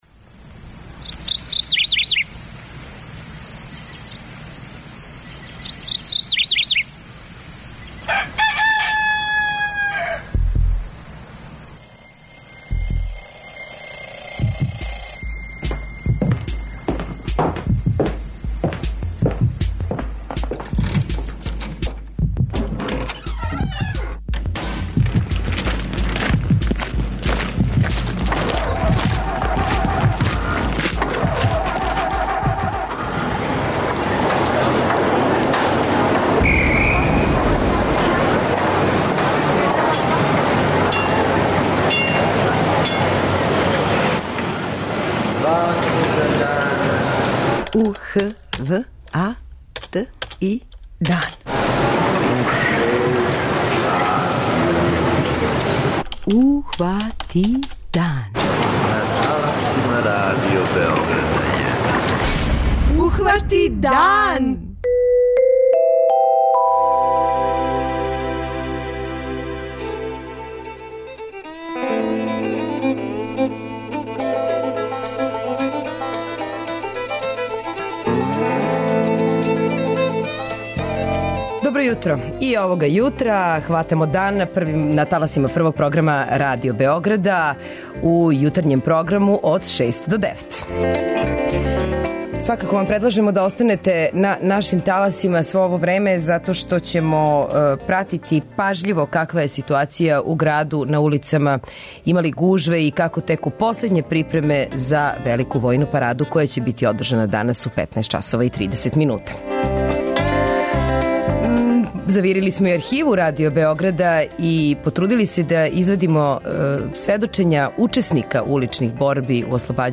У Београду се одржава Војна парада поводом 70 година од ослобођења Београда. Наши репортери прате какво је стање на улицама града.
Чућемо и шта су о ослобађању престонице, за Радио Београд, сада већ далеке 1984. године говорили сами борци, учесници те војне операције.